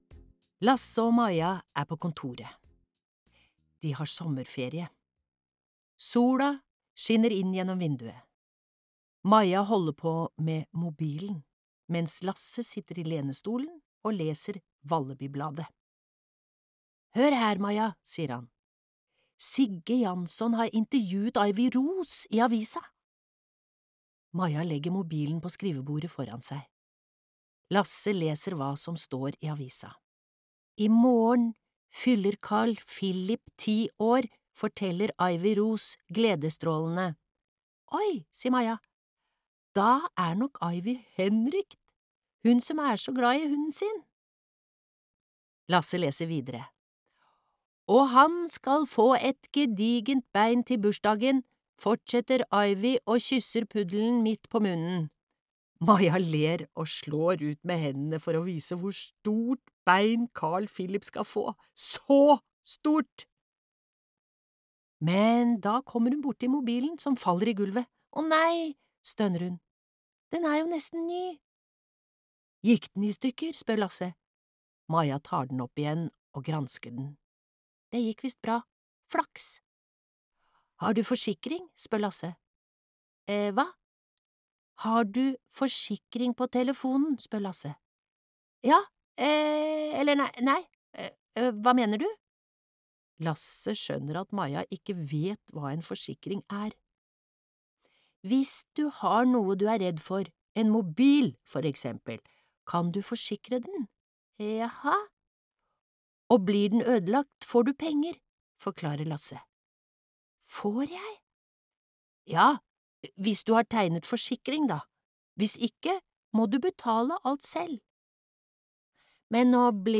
Dinosaurmysteriet (lydbok) av Martin Widmark